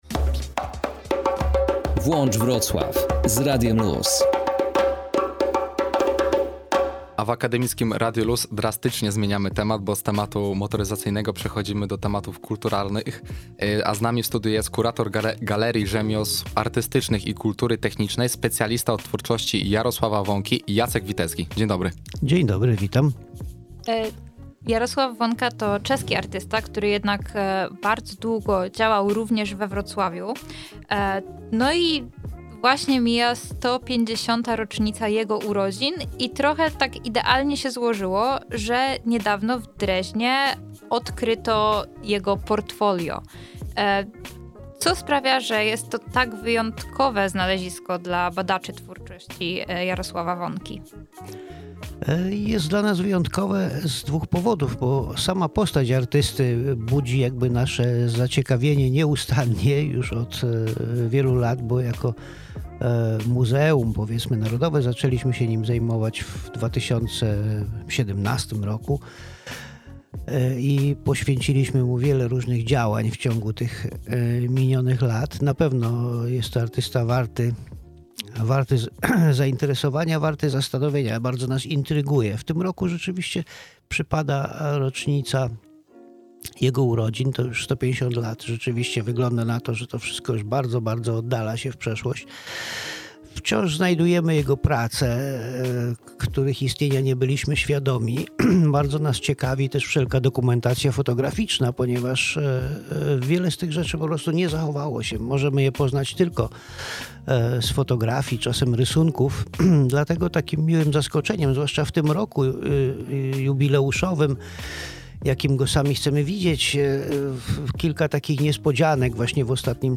rozmawiali